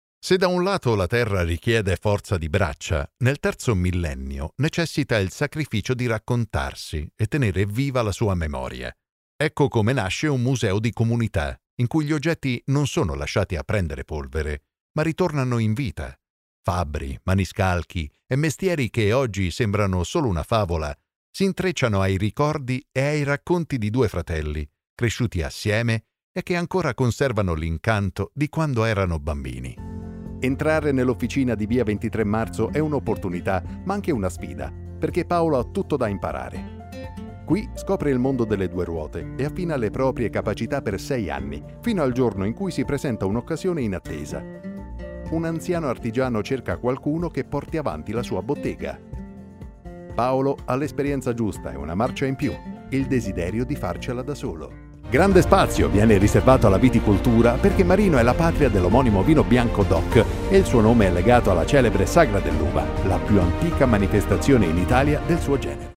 Narration
My voice is deep, mature, warm and enveloping, but also aggressive, emotional and relaxing.